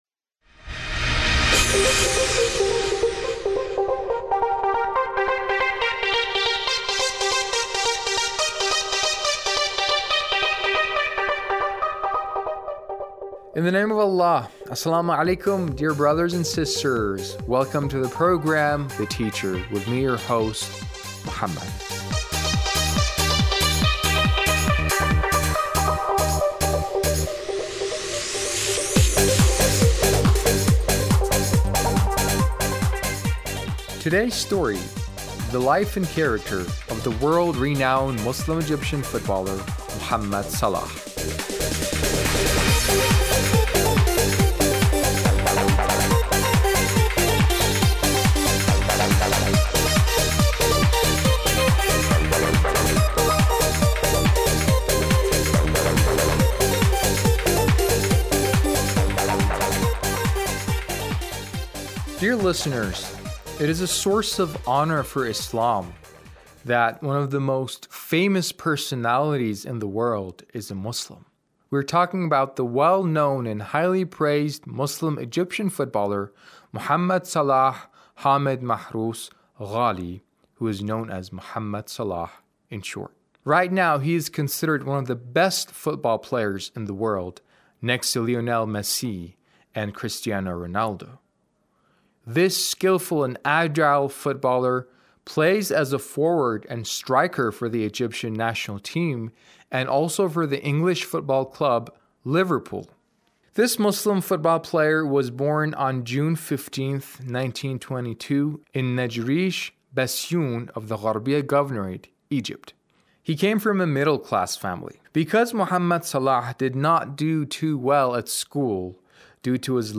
A radio documentary on the life of Mohamed Salah - 1